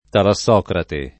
[ tala SS0 krate ]